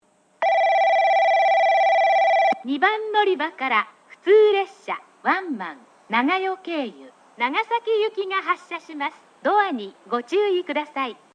huistenbosch-loca-nagasaki-5.mp3